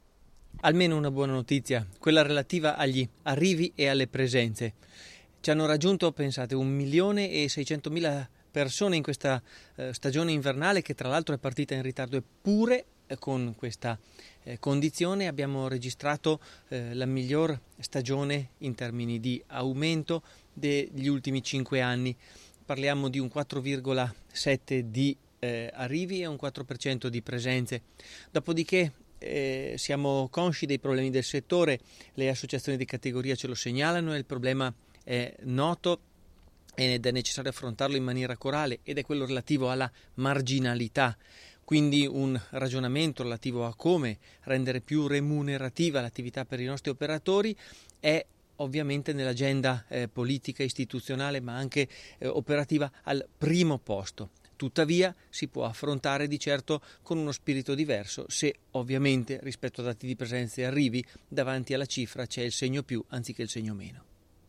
intervista_Dallapiccola.mp3